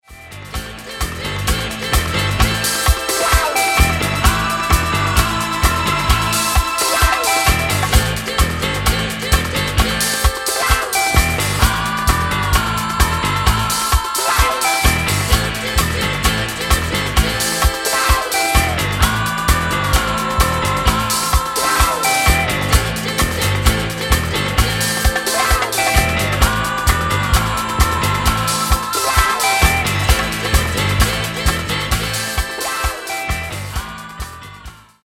Genere:   Disco | Funky | Soul |